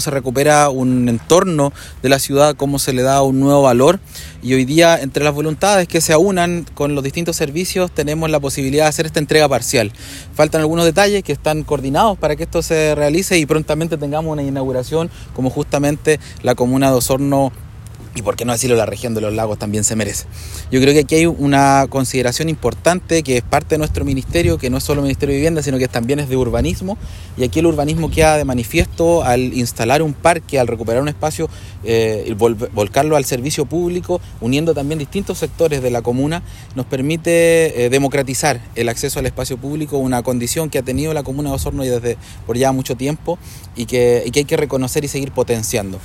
Por su parte, el seremi de Vivienda y Urbanismo, Fabián Nail, subrayó que el Parque Hott es un ejemplo de cómo avanzar en la recuperación de espacios públicos y la mejora del entorno natural en Osorno.